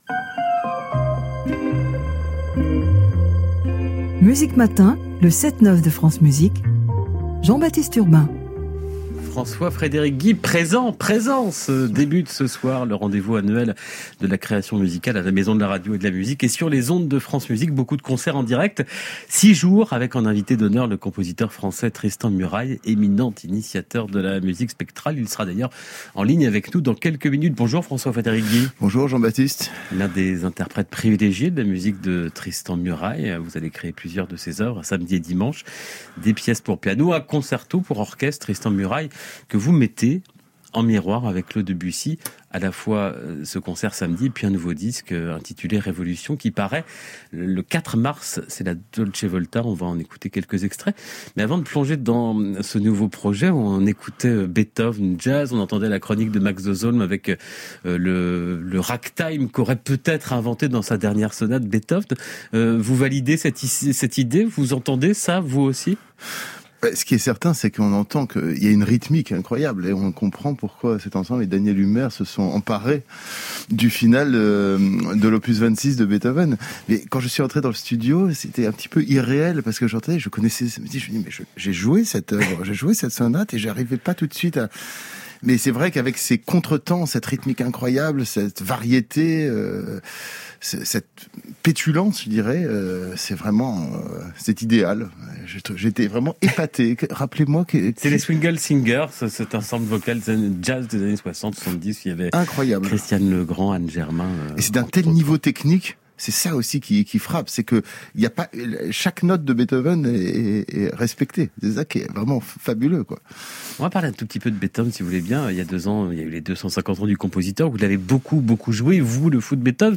diffusée sur France Musique le 8 février 2022